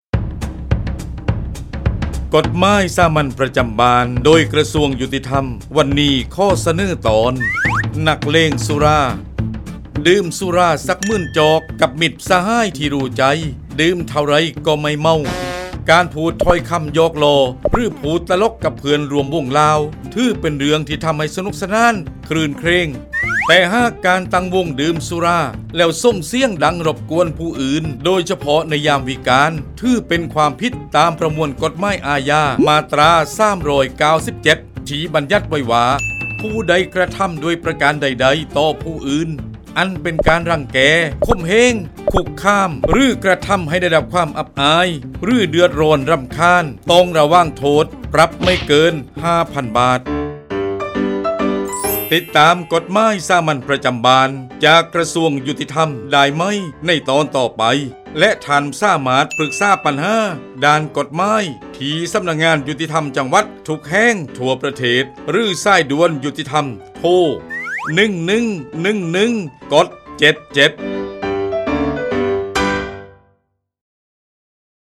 ชื่อเรื่อง : กฎหมายสามัญประจำบ้าน ฉบับภาษาท้องถิ่น ภาคใต้ ตอนนักเลงสุรา
ลักษณะของสื่อ :   บรรยาย, คลิปเสียง